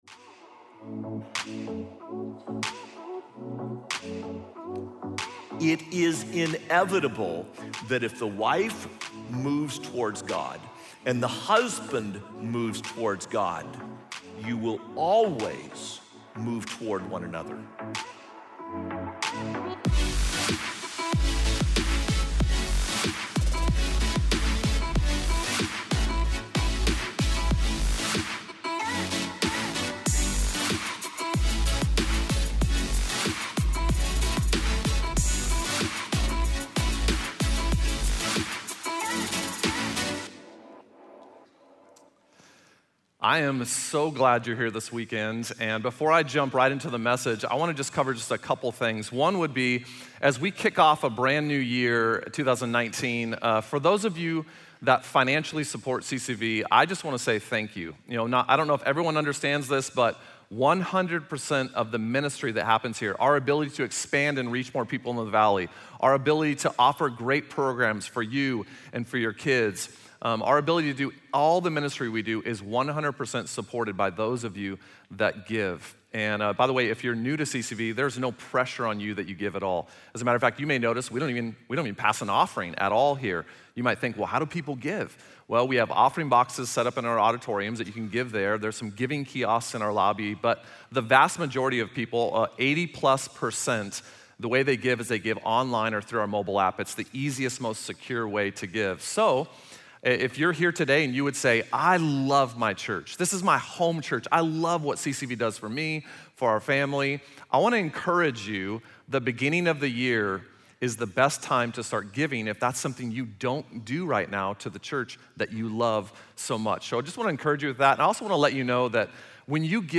The Vow: The Vow of Partnership (Full Service)